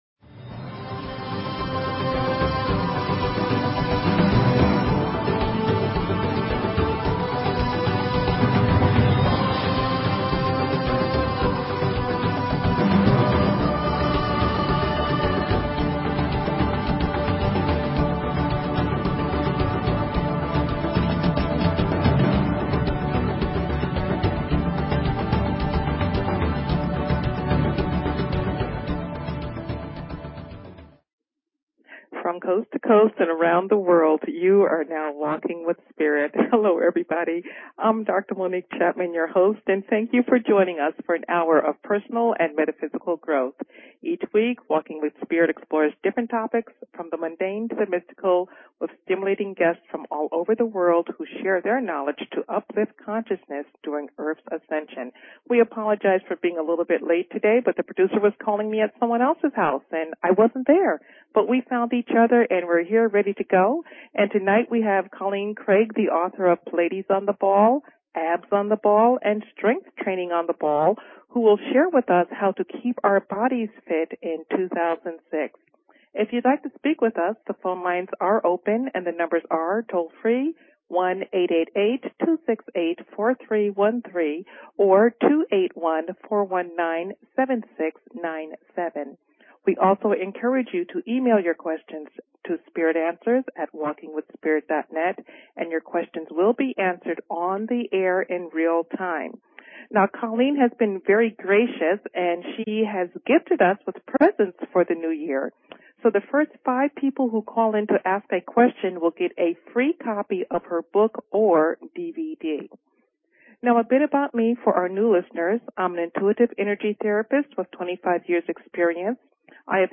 Talk Show Episode, Audio Podcast, Walking_with_Spirit and Courtesy of BBS Radio on , show guests , about , categorized as